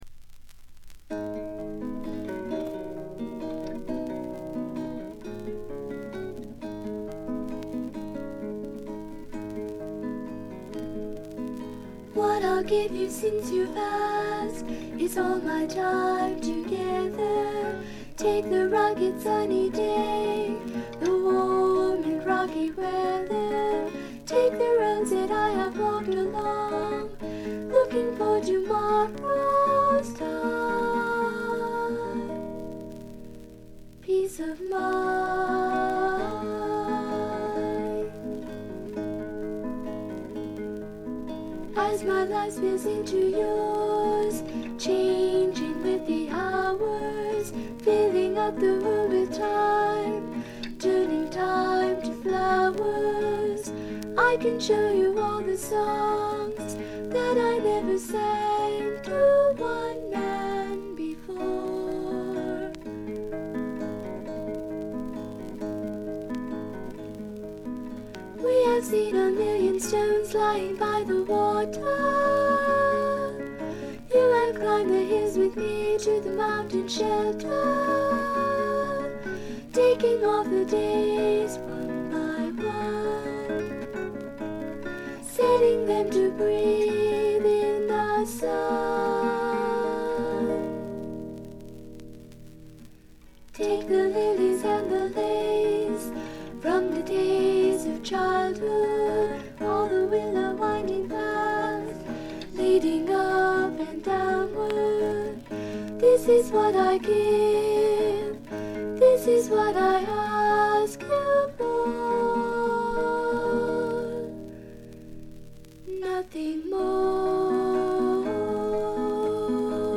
軽微なバックグラウンドノイズがずっと出ていますが鑑賞を妨げるようなものではありません。
有名曲中心のカヴァーをアコースティックギター1本の伴奏で清楚に歌います。
試聴曲は現品からの取り込み音源です。